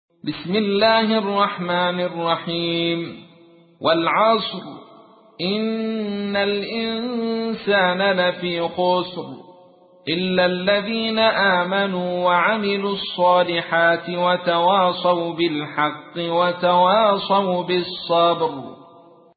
تحميل : 103. سورة العصر / القارئ عبد الرشيد صوفي / القرآن الكريم / موقع يا حسين